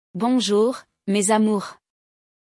O episódio de hoje do nosso podcast de francês vai trazer uma conversa no escritório, e com ela vamos aprender como falar com os colegas de trabalho nesse idioma.